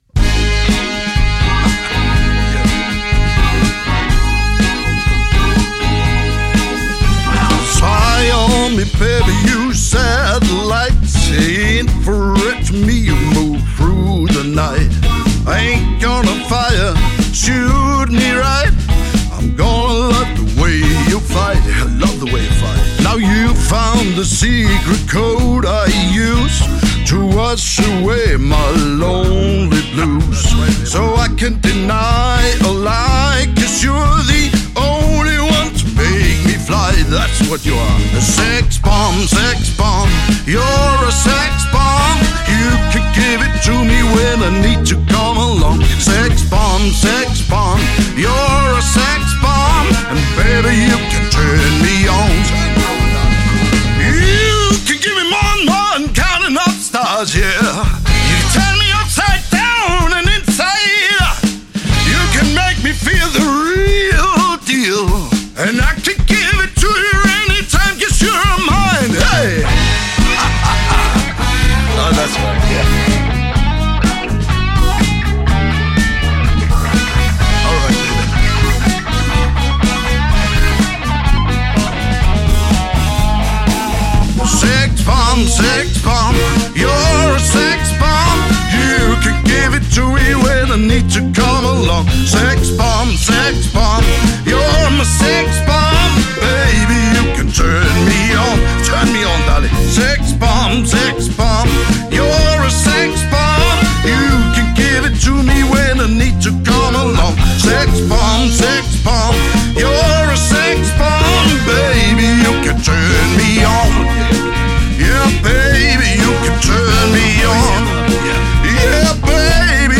LIVE Koncert
• Coverband